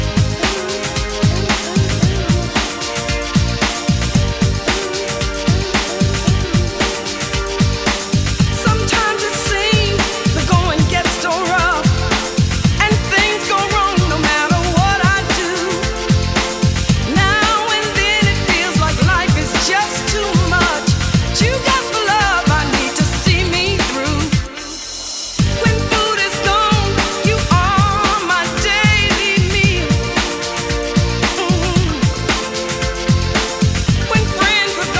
Жанр: Поп музыка / R&B / Соул / Диско